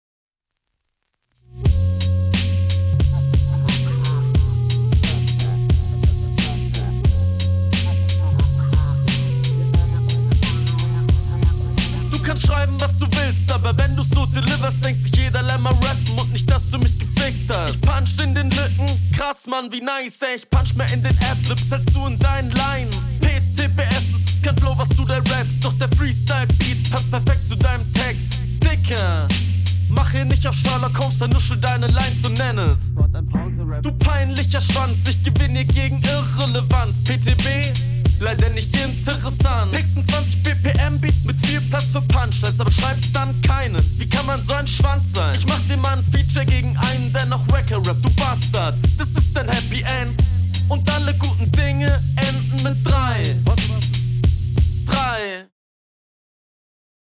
Definitiv besserer und kreativerer Stimmeinsatz und Flow …